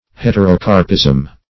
Search Result for " heterocarpism" : The Collaborative International Dictionary of English v.0.48: Heterocarpism \Het`er*o*car"pism\, n. [Hetero- + Gr.? fruit.]
heterocarpism.mp3